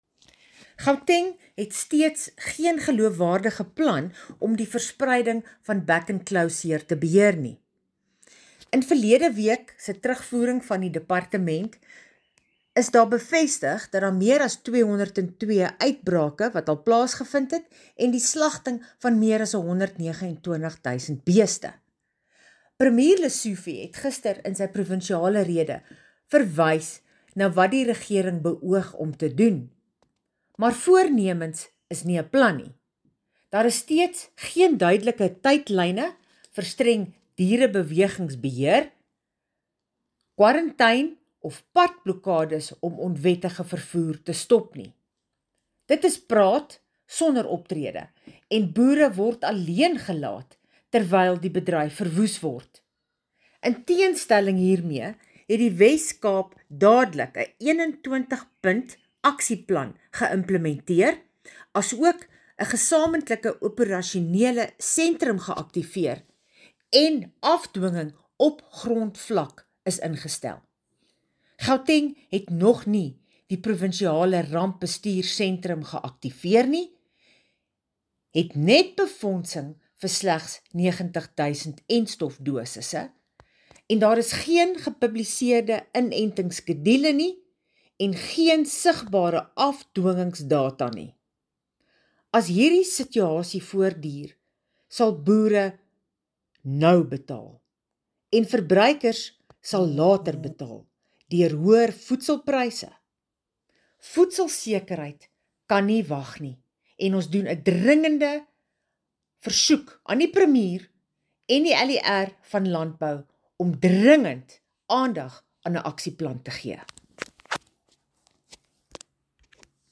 Afrikaans soundbite by Bronwynn Engelbrecht MPL.